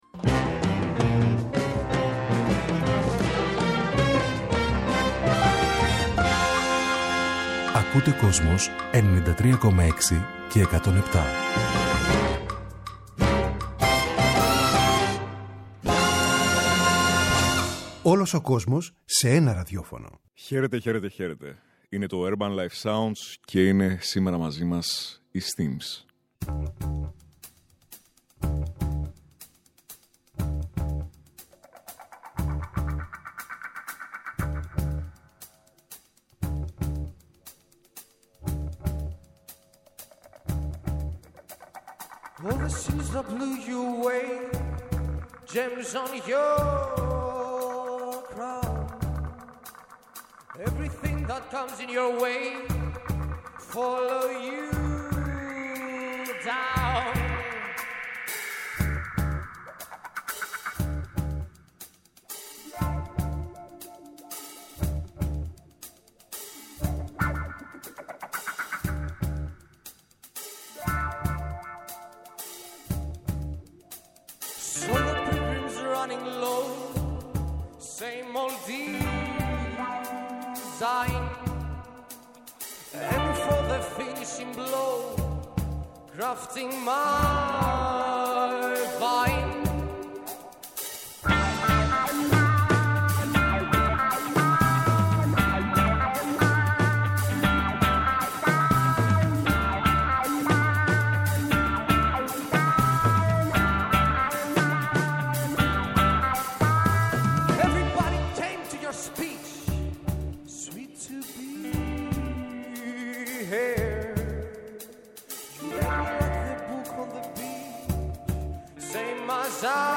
Η ψυχεδελική ροκ μπάντα με ανατολίτικα στοιχεία στον ήχο της